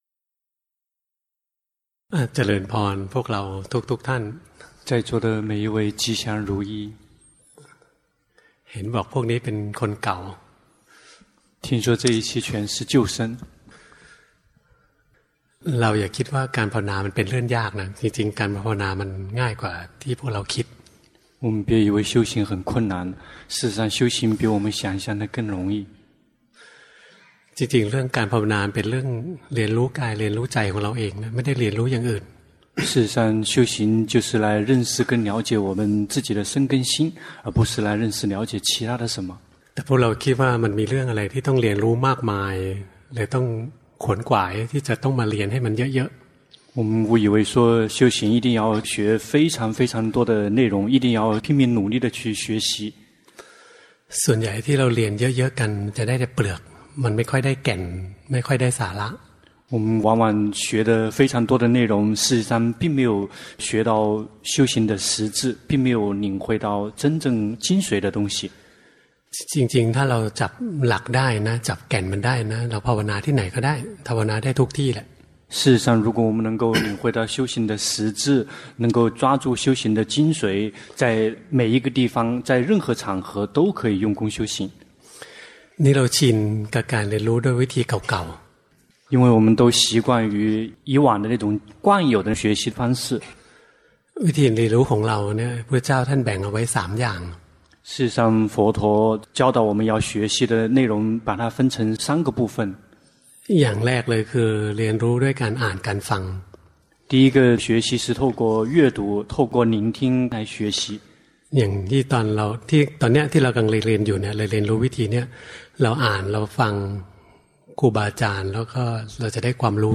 第十二屆泰國四念處禪修課程 課程翻譯